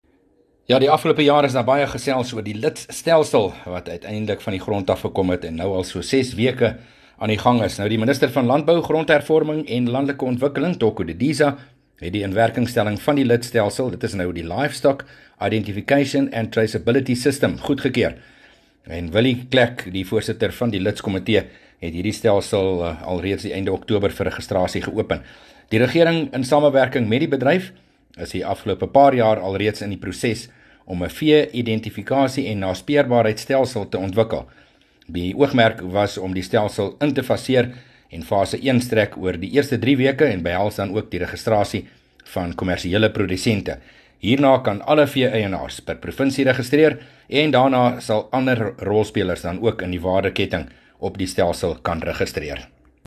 5 Dec PM berig oor ‘n vee-identifikasie en naspeurbaarheidstelsel